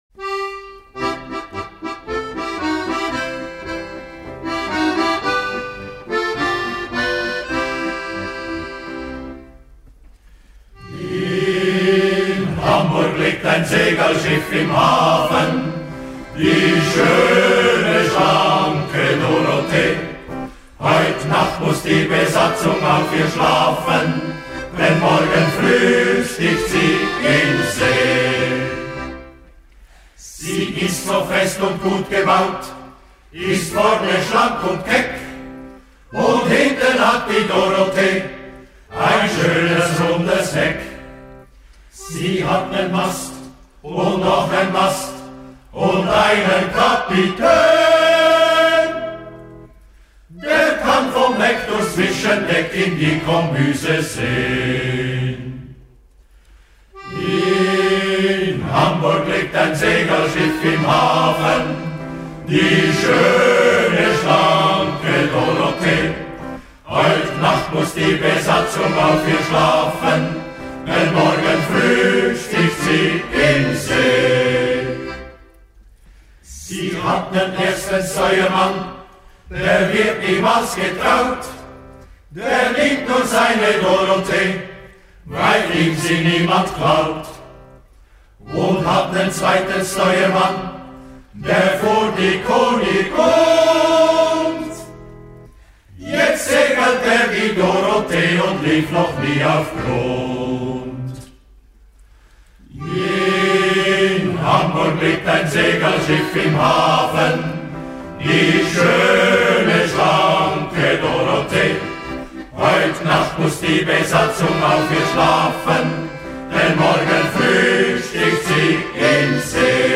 Die Shantycrew Tribschenhorn Luzern ist ein traditioneller Shanty Chor aus der Zentralschweiz, der seit vielen Jahren mit maritimen Liedern und Seemannsshantys für unvergessliche Stimmung sorgt.
Shantys sind traditionelle Arbeits- und Seemannslieder, die früher an Bord von Segelschiffen gesungen wurden.
Der Klang ist kräftig, warm und mitreissend – das Publikum wippt mit, singt mit und taucht für einen Moment in die Welt der Seefahrer ein.